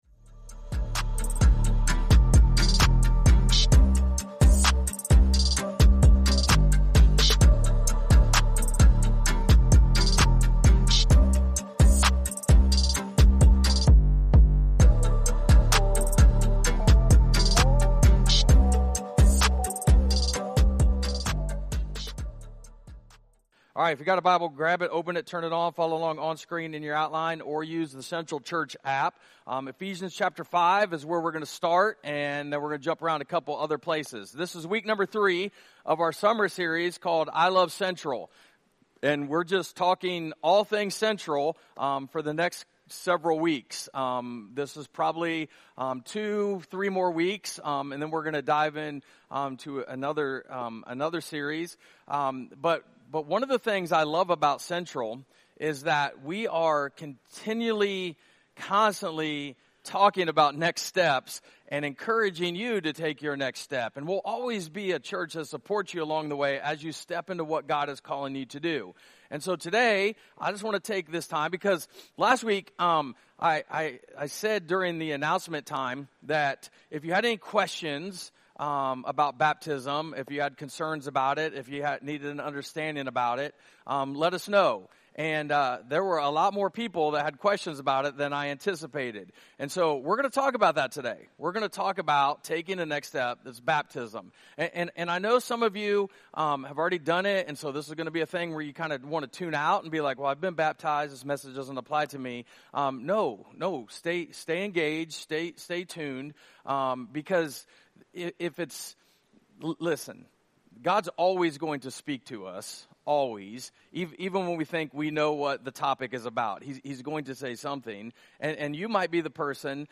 I Love Central - Week #3 - June 30, 2024 Is Baptism My Next Step?